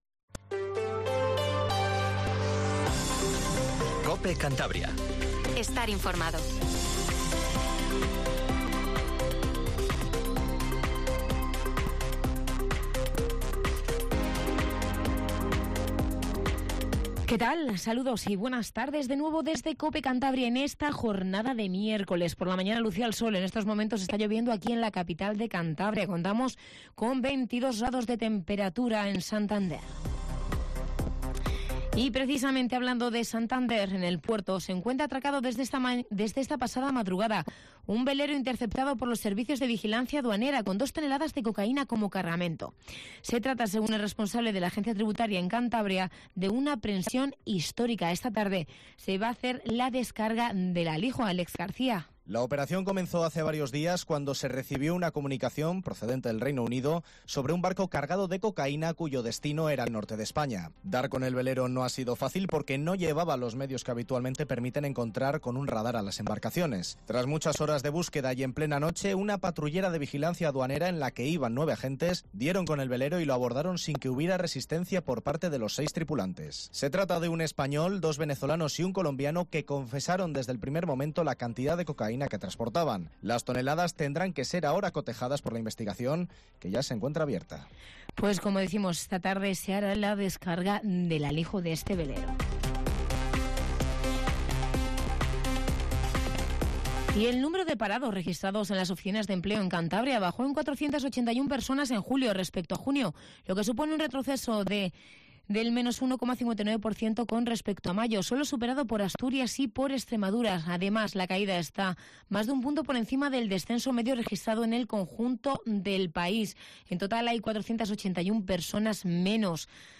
Informativo Regional 14:50